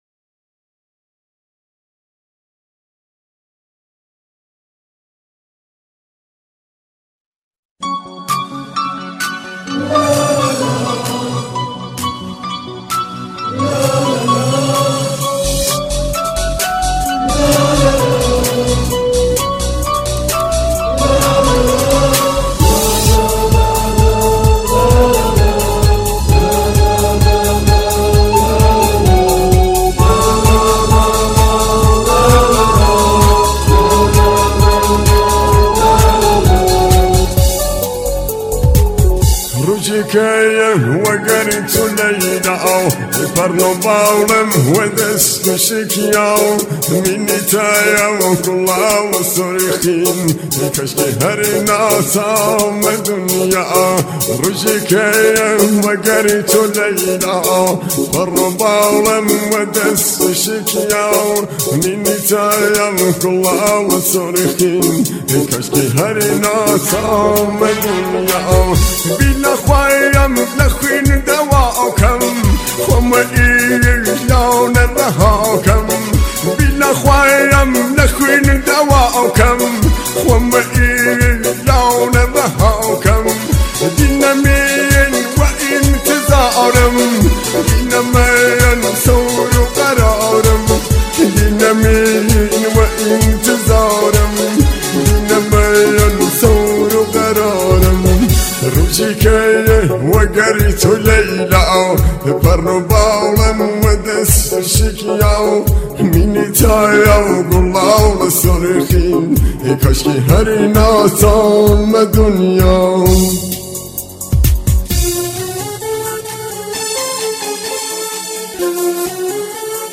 remix rhythmik jazab ve ame pasand